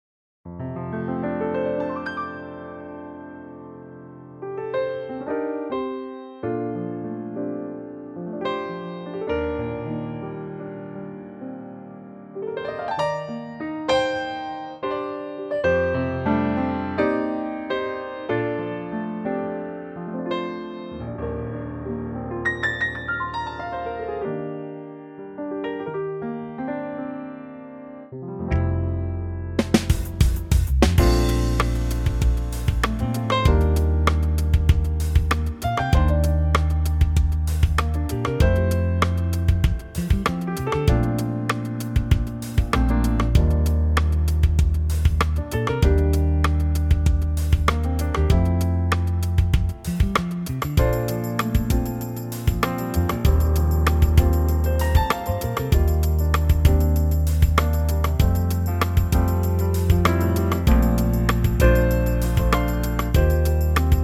Unique Backing Tracks
key - F - vocal range - F to G ( optional Bb top note)